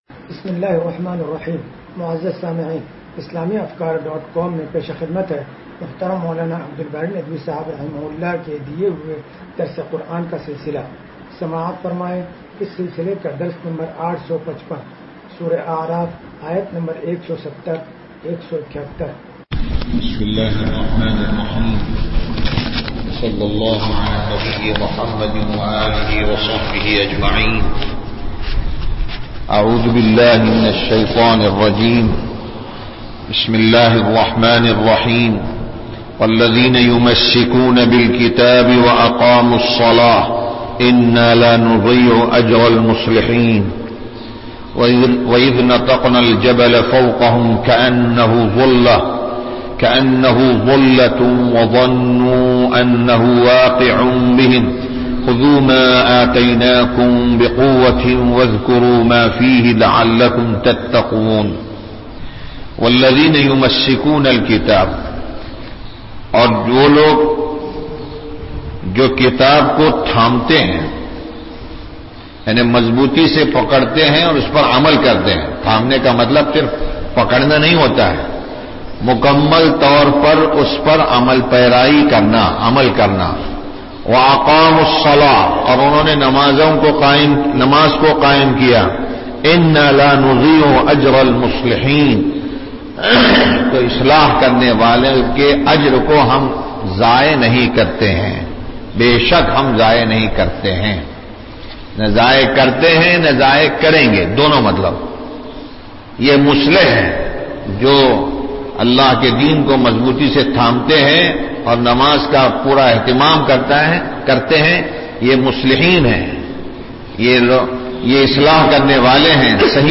درس قرآن نمبر 0855